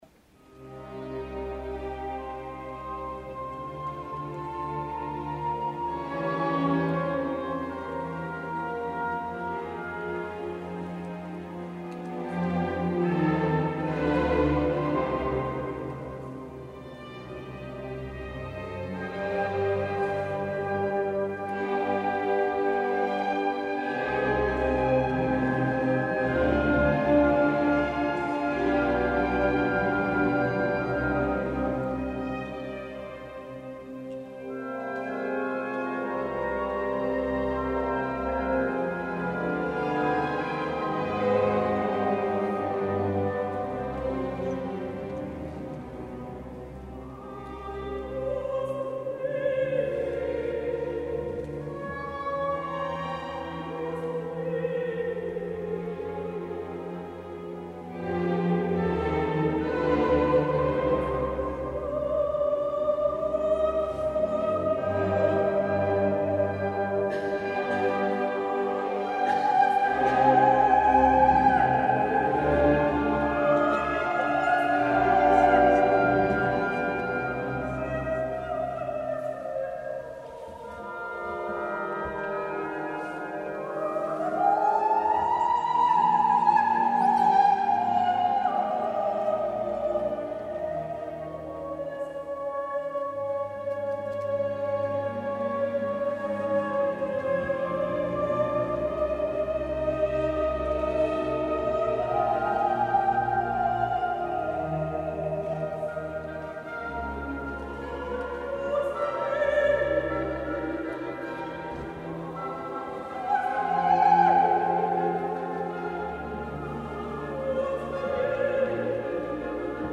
Extraits du concert de Chartres :
Kyrie (soliste